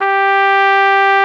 BRS CORNET05.wav